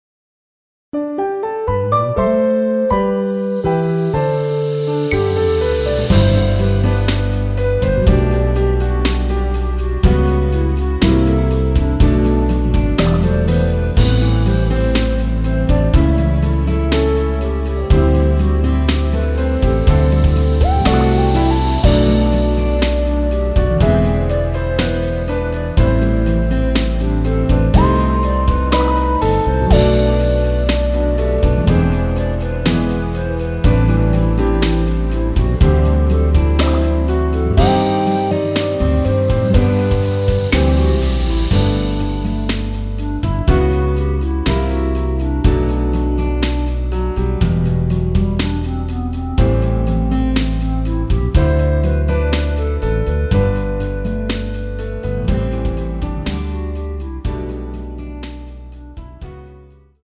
원키에서(-1)내린 멜로디 포함된 MR입니다.
앞부분30초, 뒷부분30초씩 편집해서 올려 드리고 있습니다.
중간에 음이 끈어지고 다시 나오는 이유는